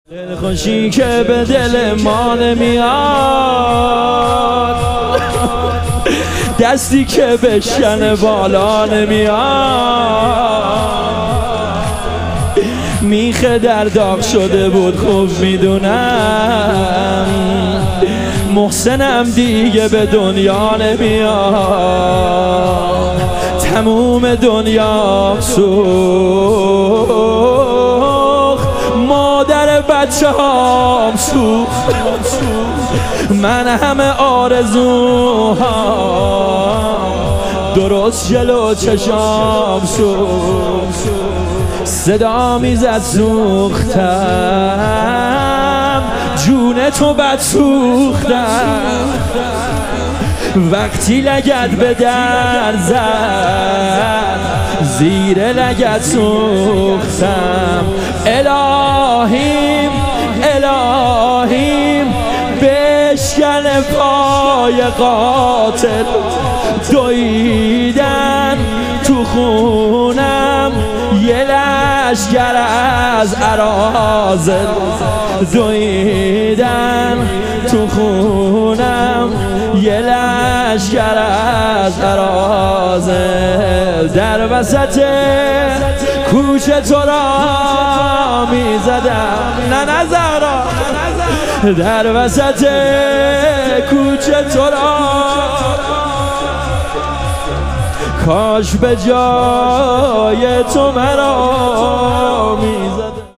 ایام فاطمیه اول - تک